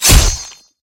skeleton_sword.ogg